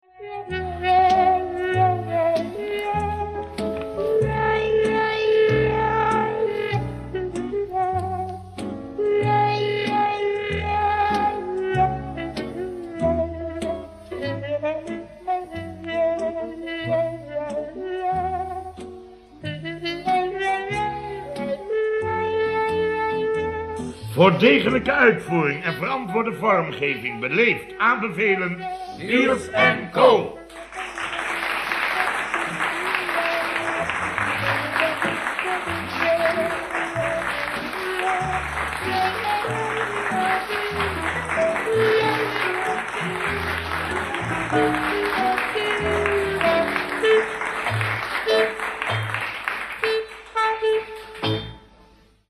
Met vaak de opening: “Verenigd aannemers bedrijf “Biels en co”, goedemorge…”
De Hoorspelserie, of radiostrip, zo u wilt, van Biels en co werd uitgezonden van 1968 tot 1973 door de AVRO. Deze serie gaat over de beslommeringen van aannemersbedrijf “Biels en co”, waarbij de vermakelijke dialogen en spraakverwarringen zorg dragen voor komische verwikkelingen.